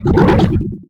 CosmicRageSounds / ogg / general / combat / creatures / alien / he / attack1.ogg
attack1.ogg